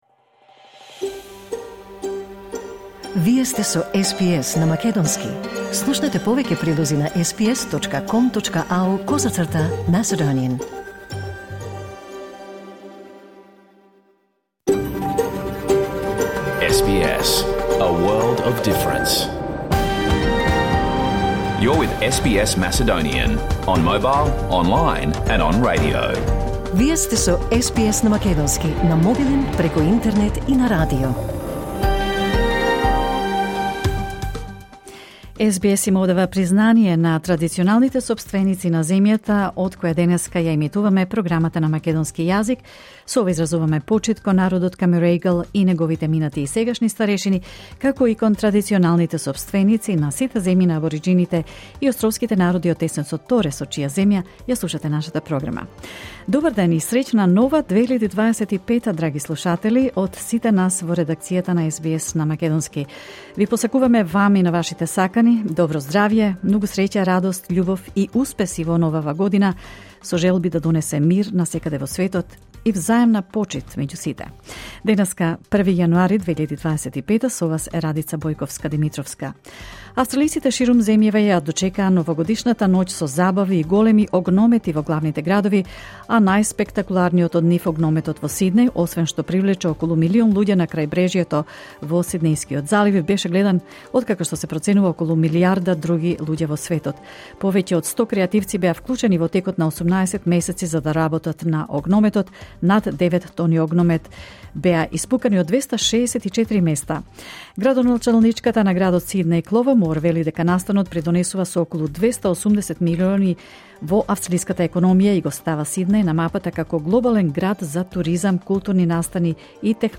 SBS Macedonian Program Live on Air 1st January 2025